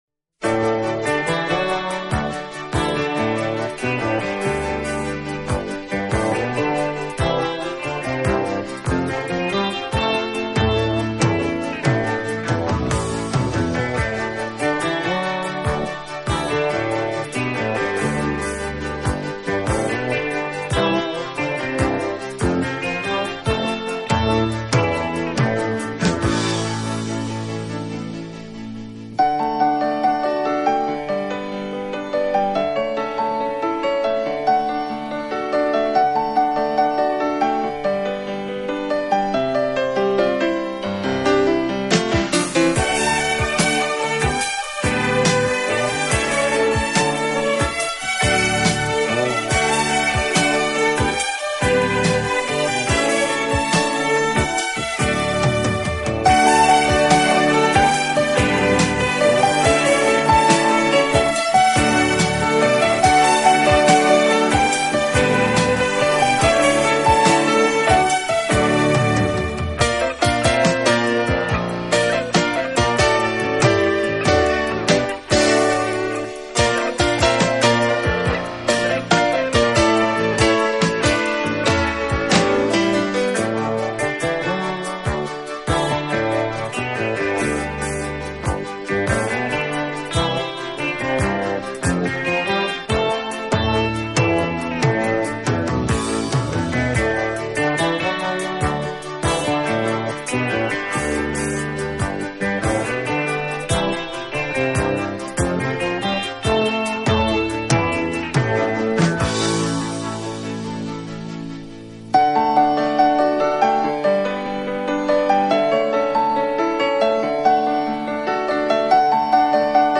依然那么富于激情。